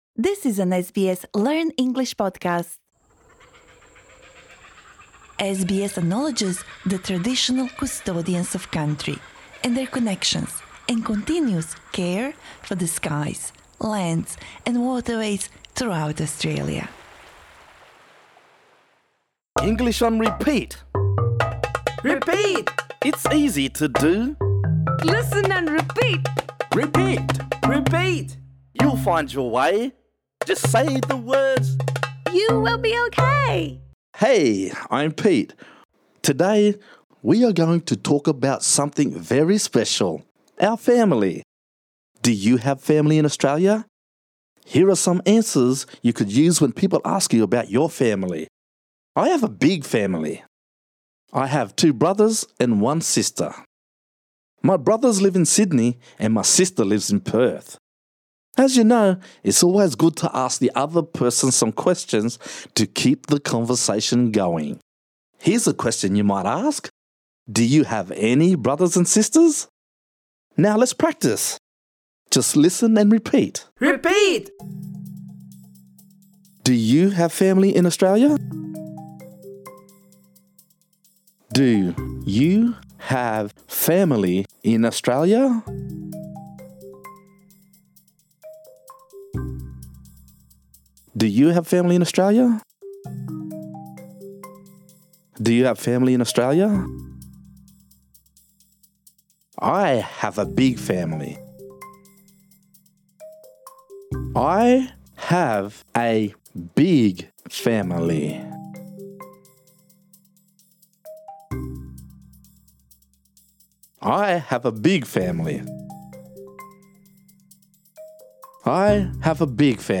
This lesson is designed for easy-level learners. In this episode, we practise saying the following phrases: Do you have family in Australia?